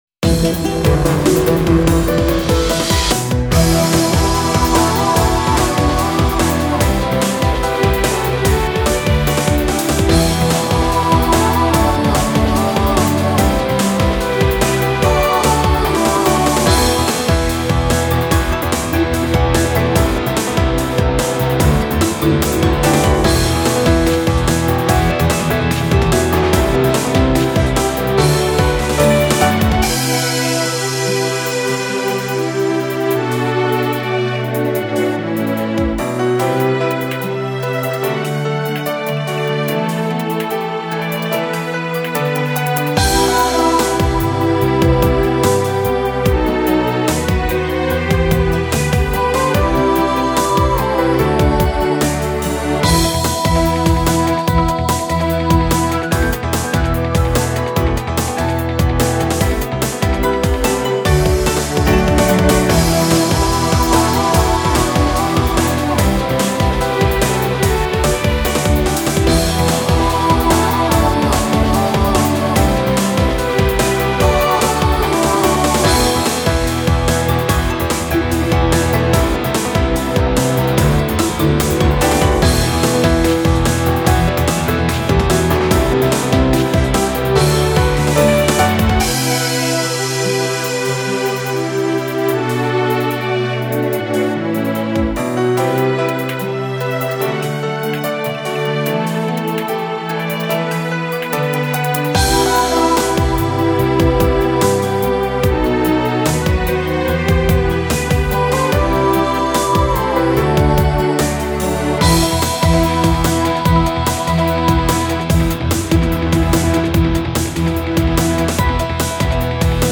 多重録音コーラスを入れた、少しオリエンタルな雰囲気のある戦闘曲です。
ループしません。